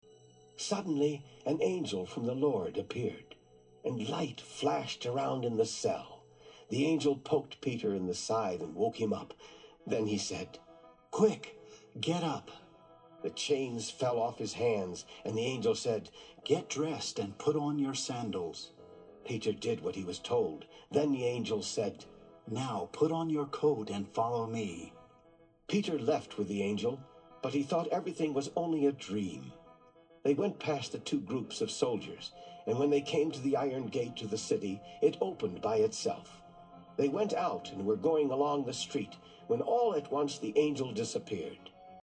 Speaking Exercise
Try to imitate the speaker’s intonation, the rhythm of his speech, and the stresses he puts on words and syllables.